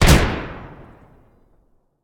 rifle3.ogg